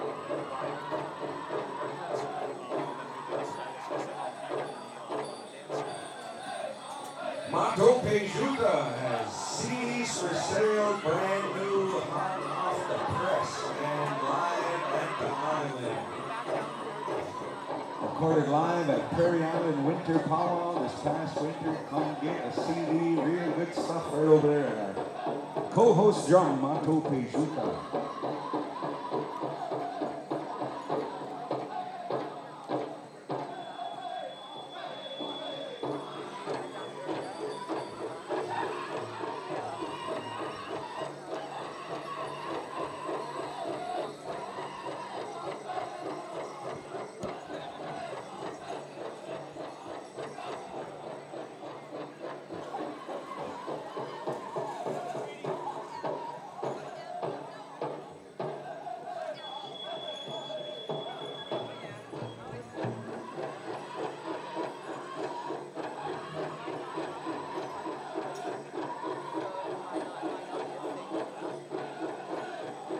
Menominee Homecoming Powwow August 2023